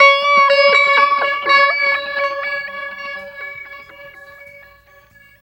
43 GUIT 2 -L.wav